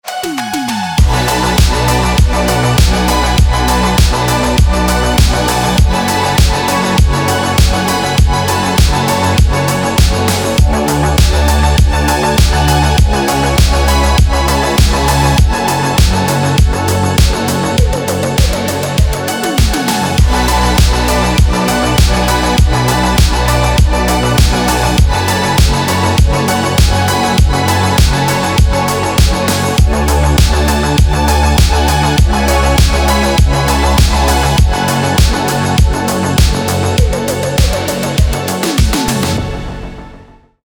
Громкие рингтоны , Танцевальные рингтоны